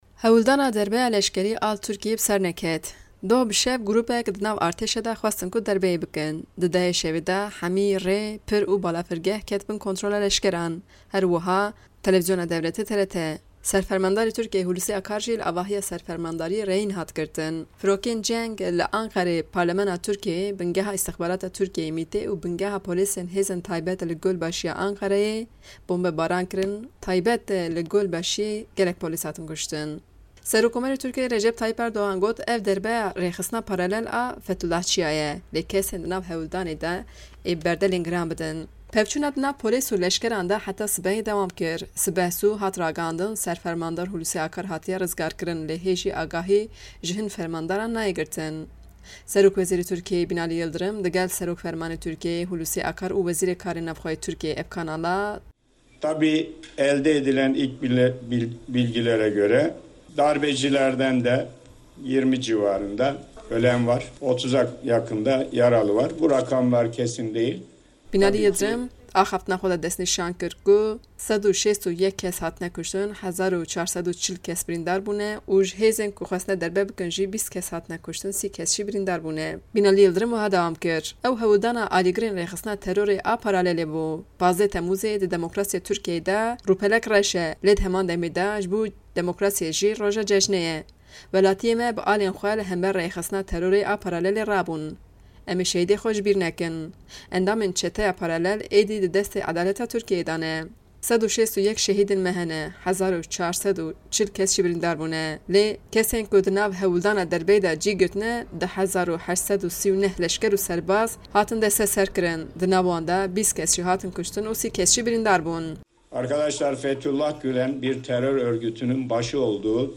Raport bi deng